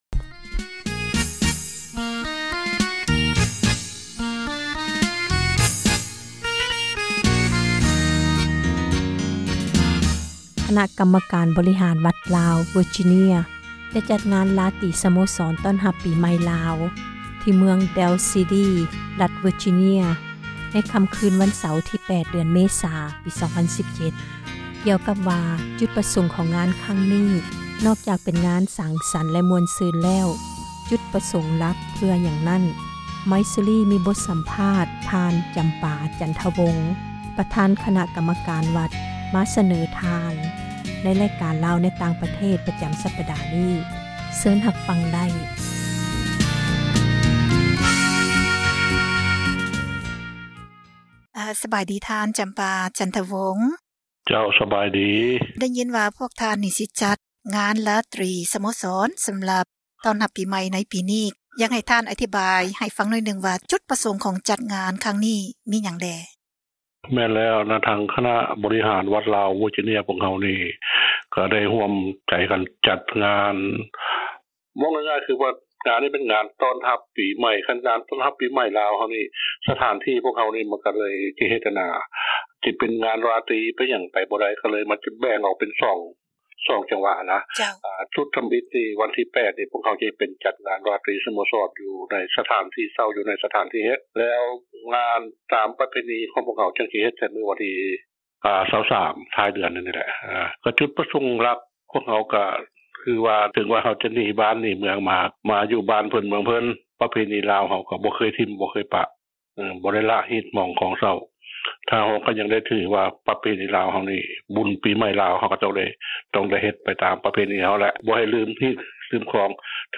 ມີສັມພາດ